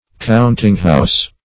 Countinghouse \Count"ing*house`\ (kount"?ng-hous`), Countingroom